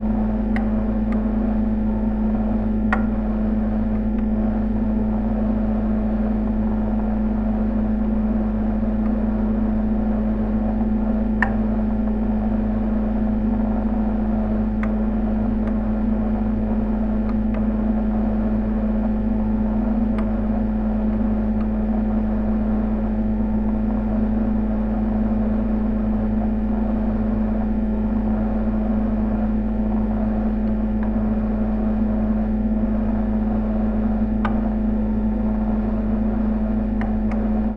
Звуки морозильной камеры
Морозильник гудит на кухне звук снаружи